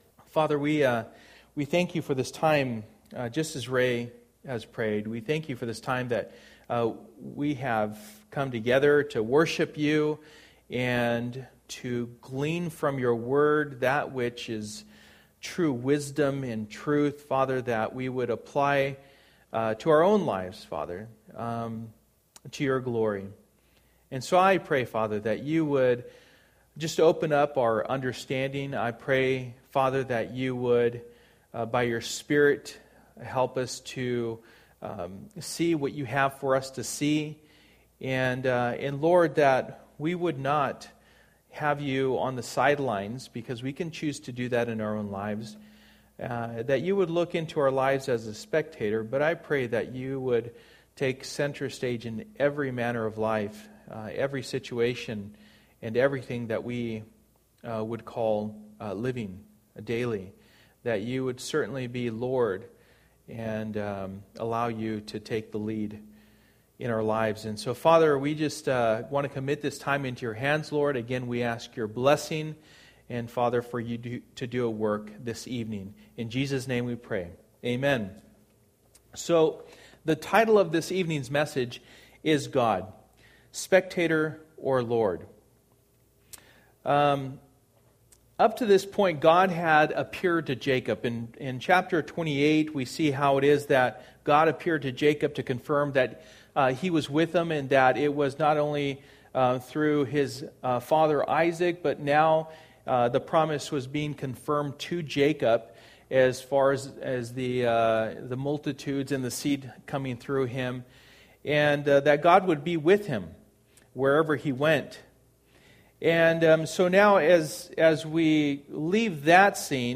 Through the Bible Passage: Genesis 29:1-35 Service: Wednesday Night %todo_render% « Do You Have Evidence of Salvation?